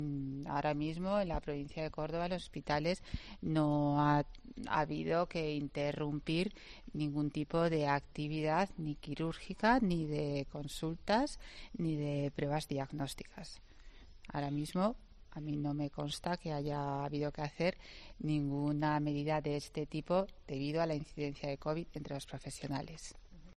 Escucha a la delegada de Salud de la Junta, María Jesús Botella, sobre la situación pandémica en hospitales
En este sentido y en declaraciones a los periodistas, en el marco de su participación en un desayuno organizado por el Colegio de Enfermería de Córdoba, Botella ha explicado que "a los profesionales sanitarios se les hace un seguimiento mucho más estricto respecto a la posibilidad de tener o no tener Covid".